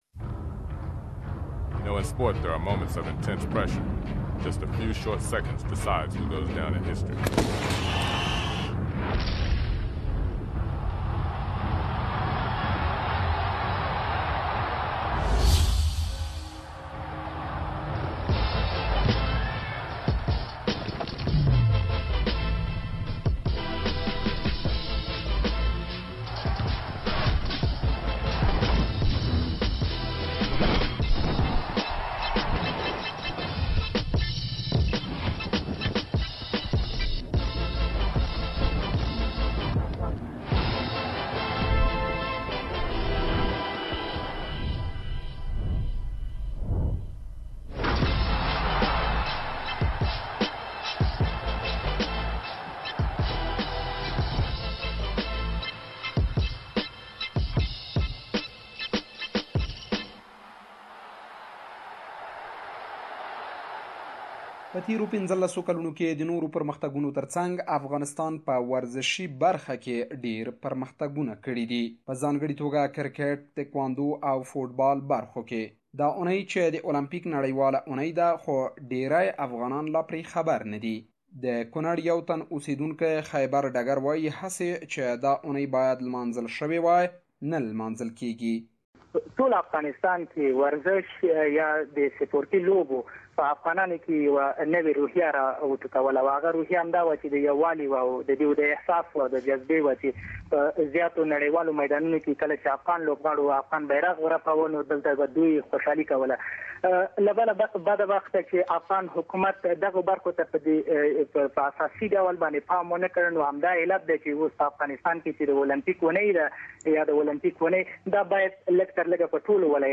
Afghanistan has significant achievements in sports but this day hasn't been celebrated widely. We asked ordinary Afghans and officials why.